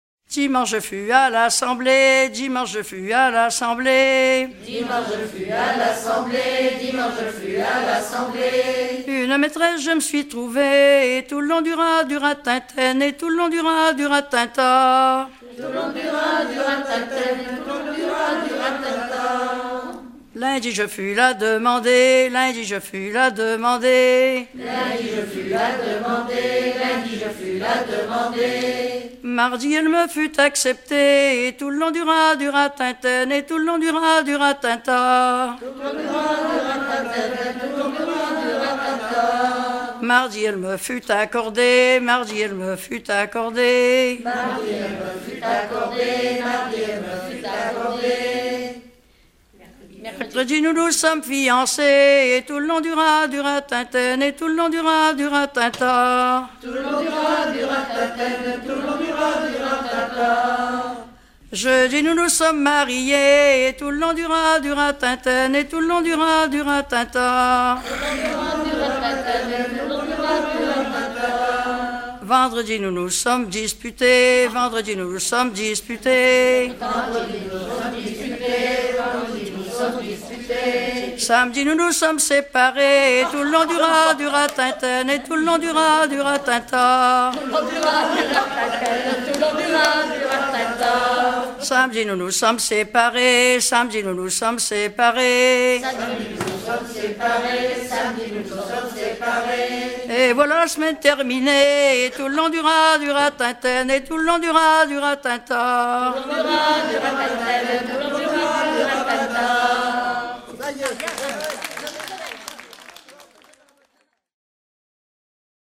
Falleron ( Plus d'informations sur Wikipedia ) Vendée
Genre énumérative
Pièce musicale éditée